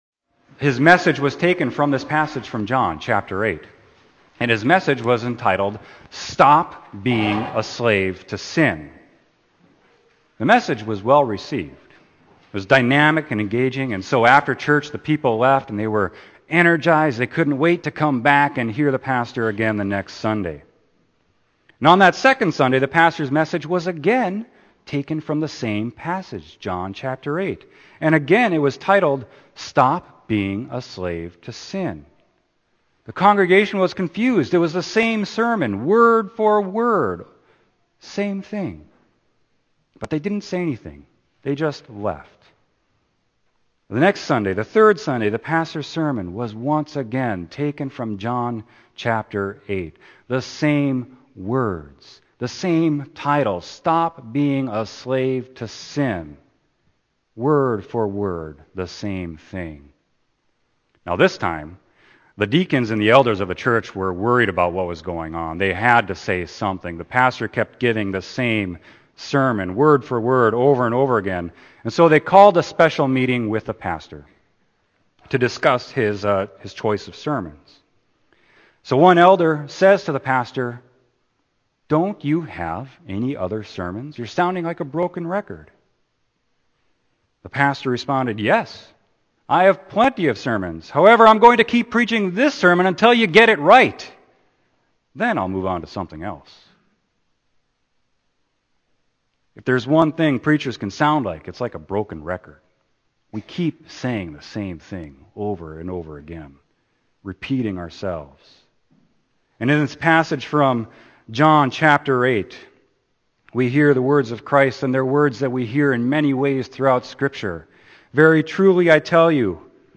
Sermon: John 8.31-36